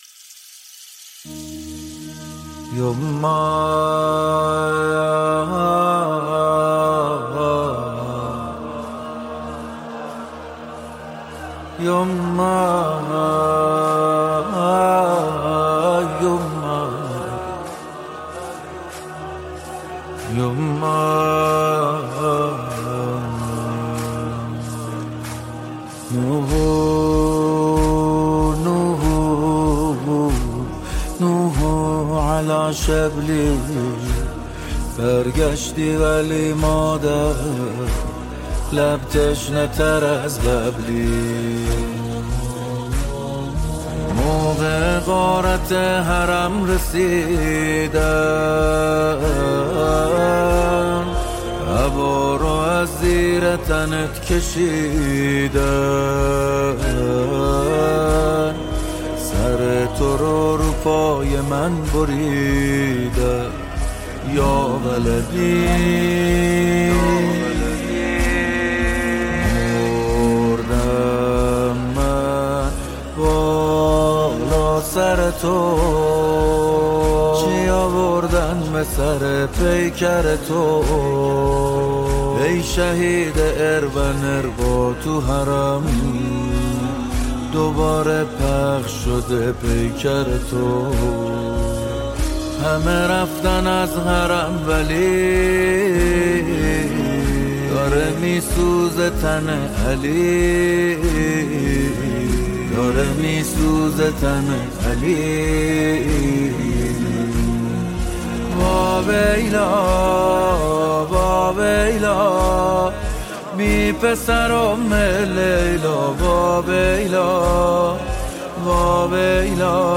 نوحه جدید